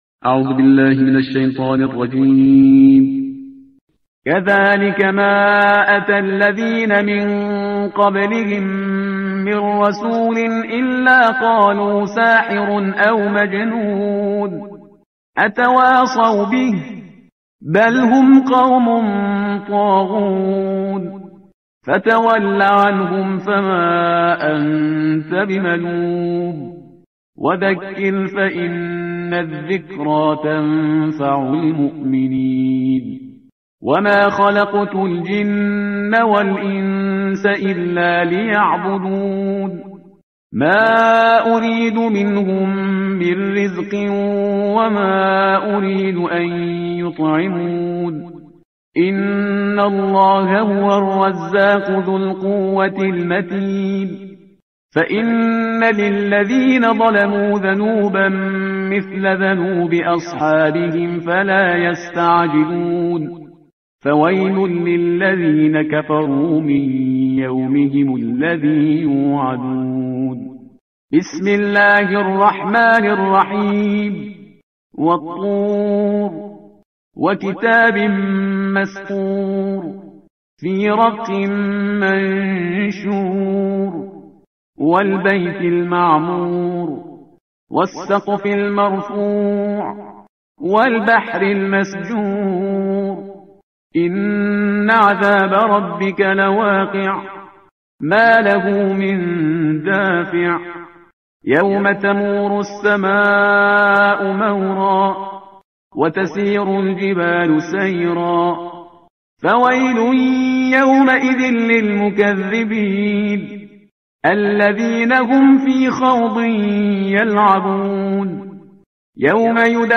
ترتیل صفحه 523 قرآن با صدای شهریار پرهیزگار